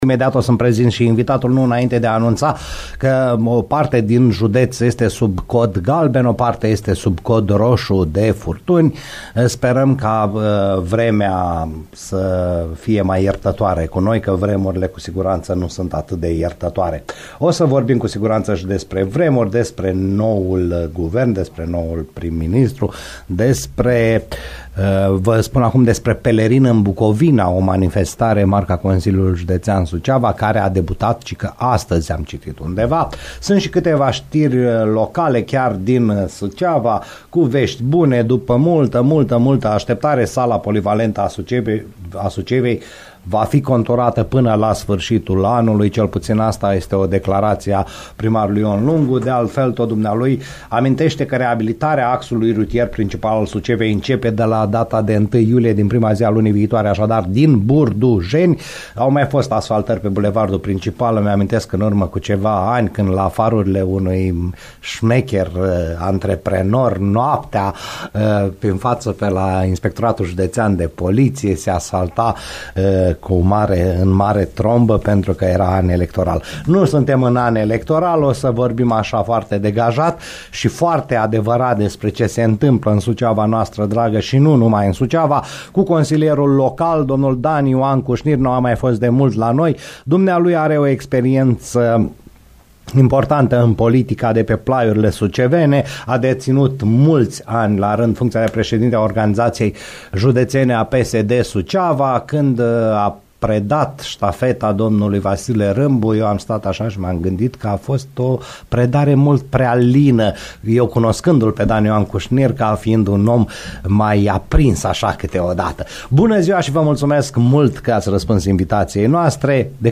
PUNCTUL PE I, consilierul local Dan Cușnir (PSD)